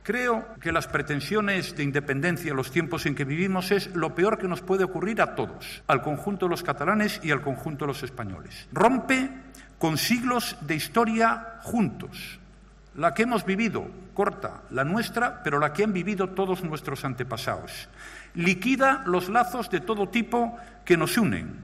Rajoy ha hecho esa llamada en su intervención en las jornadas del Círculo de Economía de Sitges, en Barcelona, en la que ha sido su primera visita a Cataluña desde que se conoció un borrador de la denominada ley de desconexión que prepara la Generalitat.